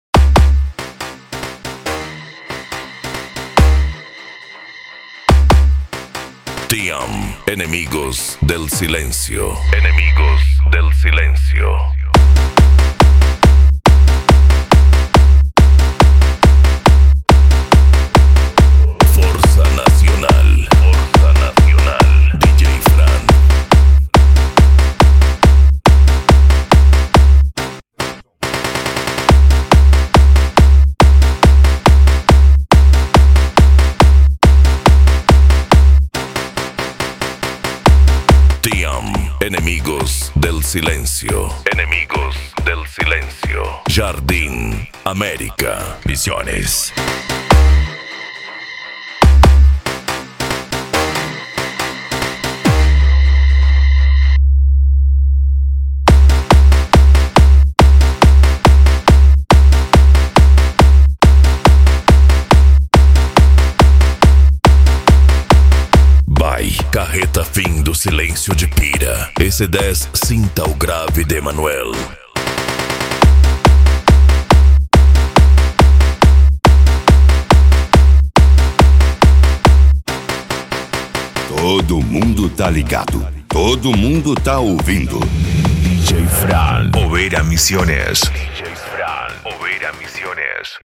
Bass
Mega Funk
Remix